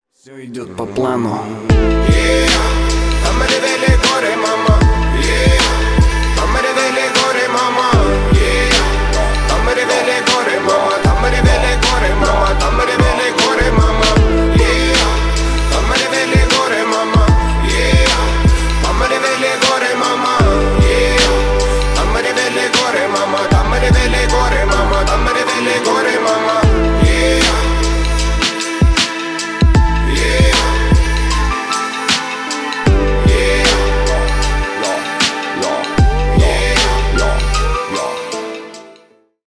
Ремикс # Рэп и Хип Хоп